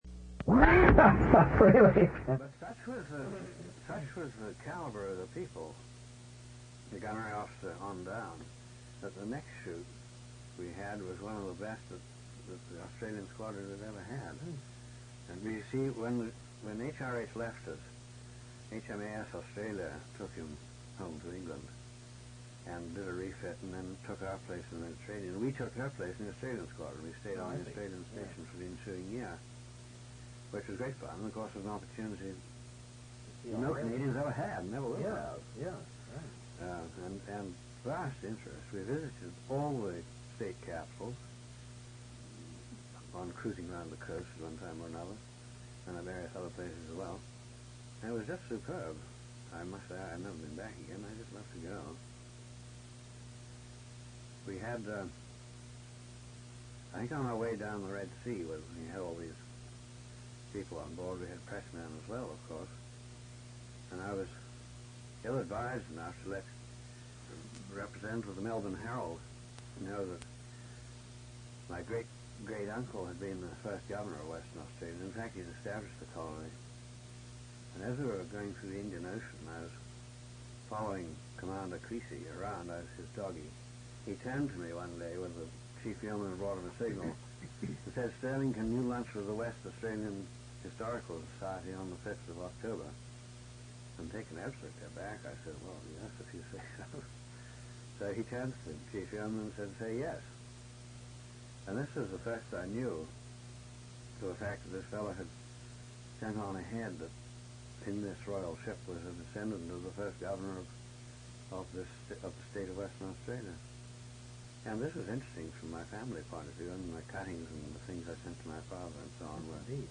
An interview/narrative